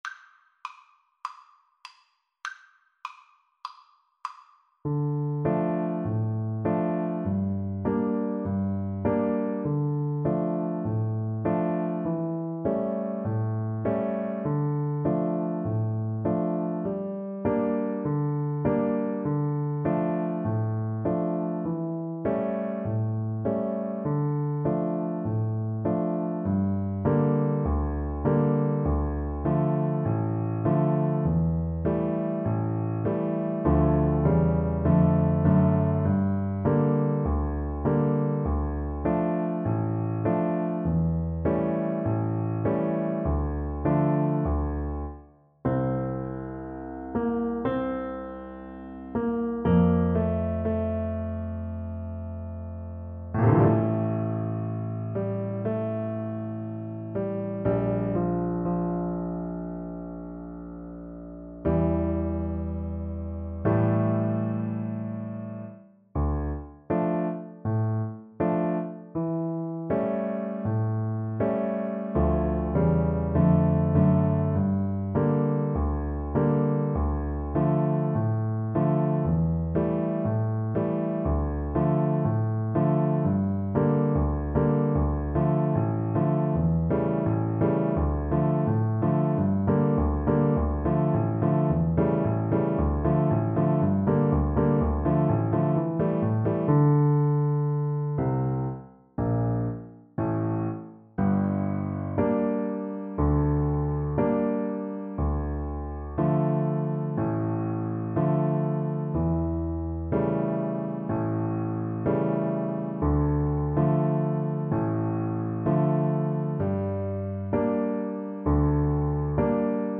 Play (or use space bar on your keyboard) Pause Music Playalong - Player 1 Accompaniment transpose reset tempo print settings full screen
D minor (Sounding Pitch) (View more D minor Music for Piano Duet )
Traditional (View more Traditional Piano Duet Music)